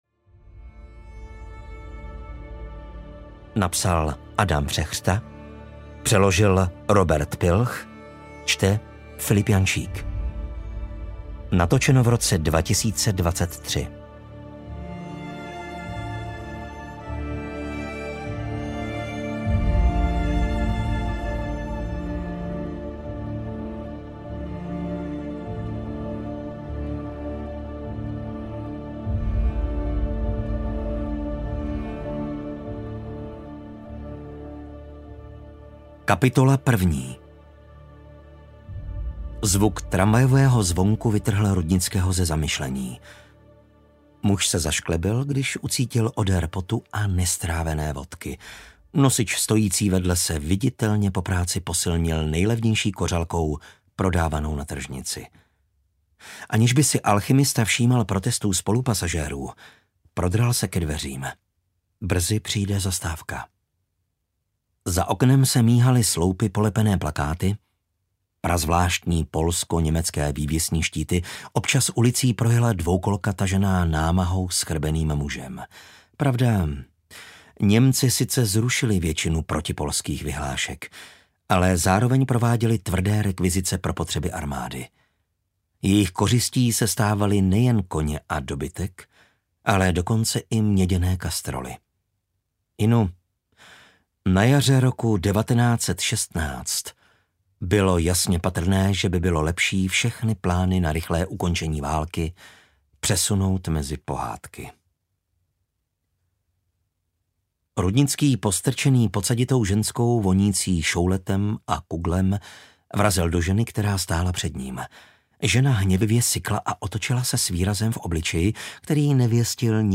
Místodržící audiokniha
Ukázka z knihy